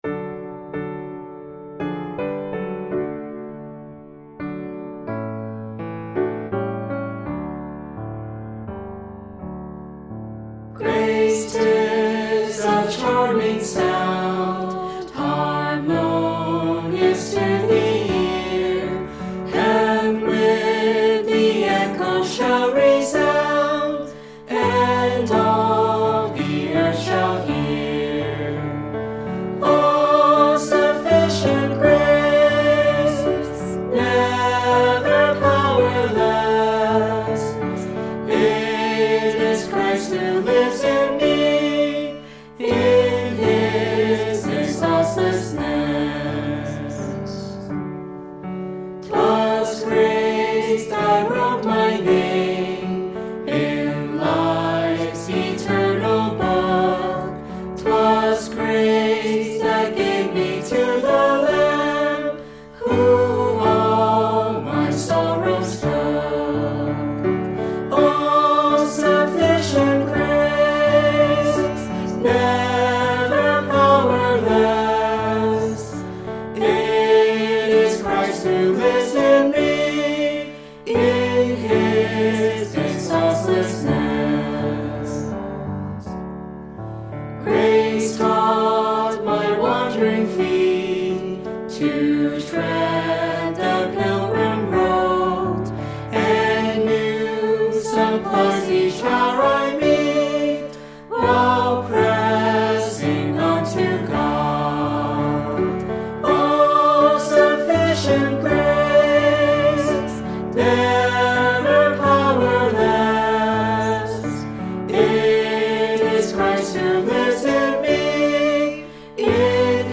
Hymn: Grace!
God bless the choir that sang this song.